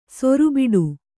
♪ soru biḍu